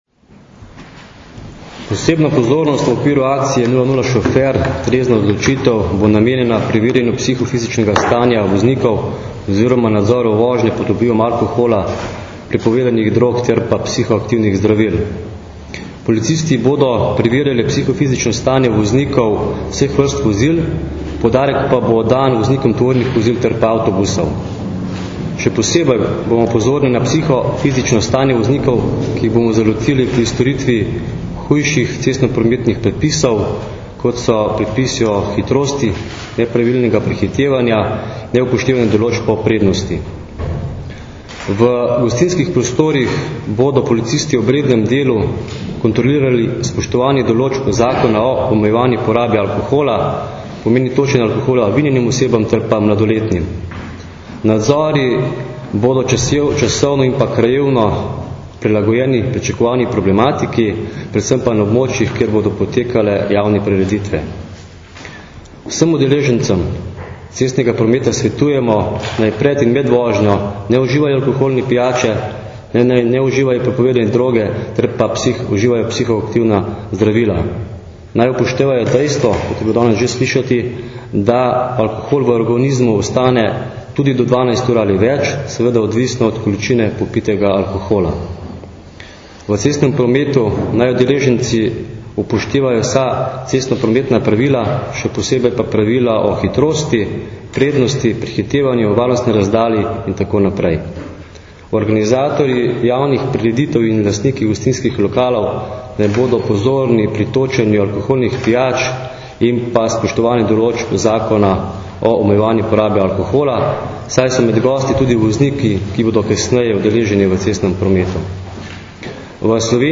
Za volan ali krmilo samo trezni – informacija z novinarske konference